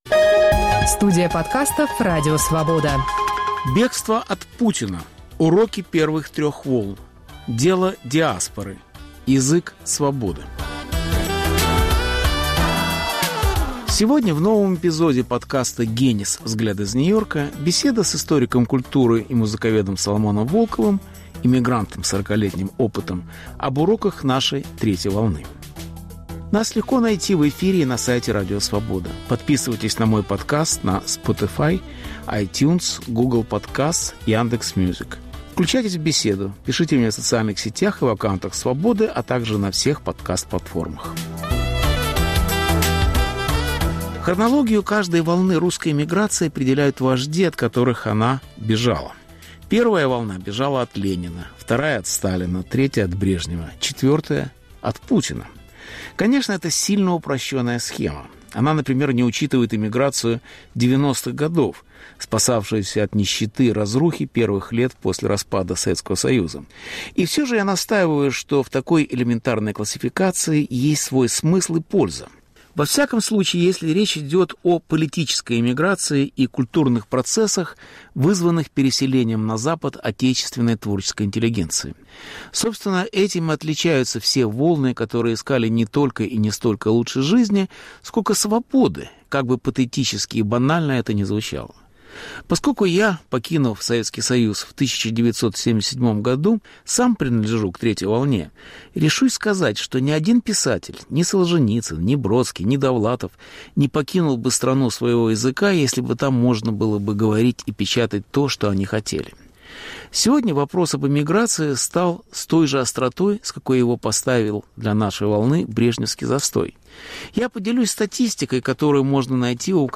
Беседа с Соломоном Волковым об уроках эмиграции в диаспоре и метрополии. Повтор эфира от 29 ноября 2021 года.